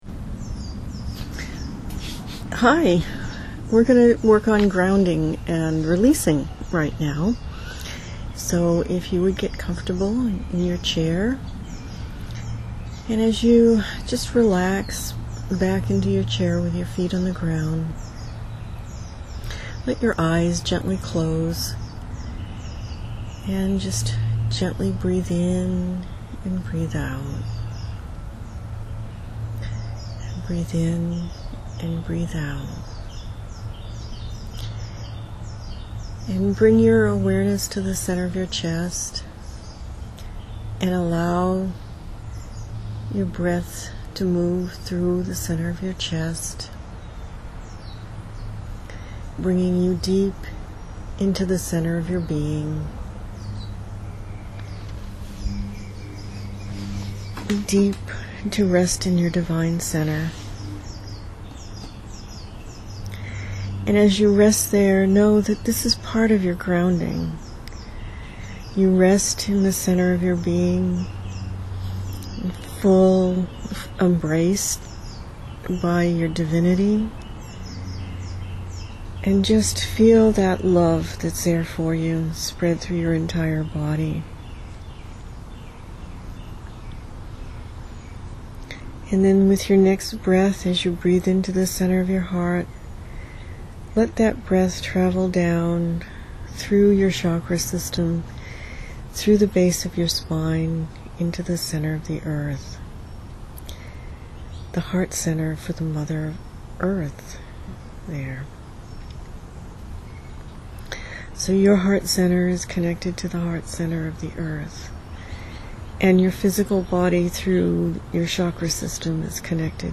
I asked the guides to work with me to do a grounding meditation. They worked with a grounding and a way to move energy through your body.